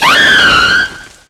Cri de Majaspic dans Pokémon X et Y.